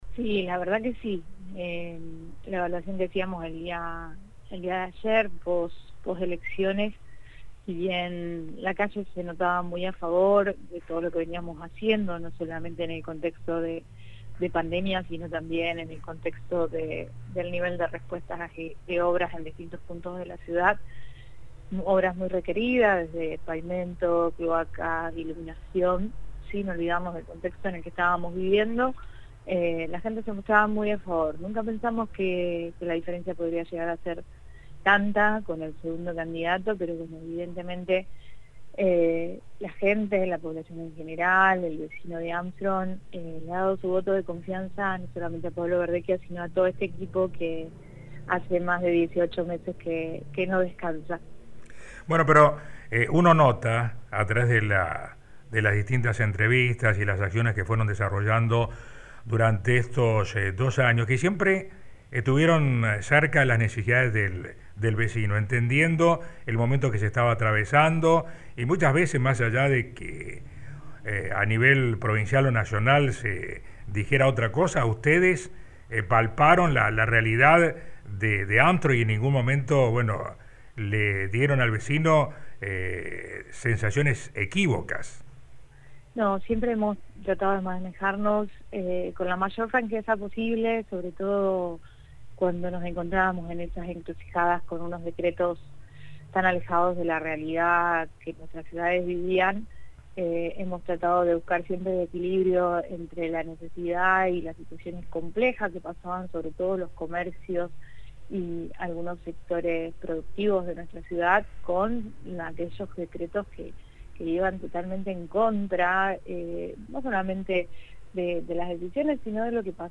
Daniela Pérez habla del triunfo en las Elecciones Legislativas 2021